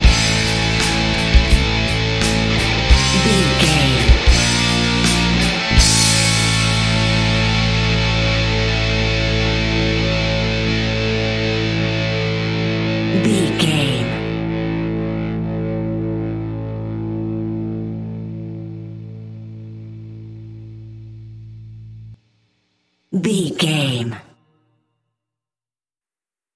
Mixolydian
SEAMLESS LOOPING?
DOES THIS CLIP CONTAINS LYRICS OR HUMAN VOICE?
WHAT’S THE TEMPO OF THE CLIP?
electric guitar
hard rock
lead guitar
bass
drums
aggressive
energetic
intense
nu metal
alternative metal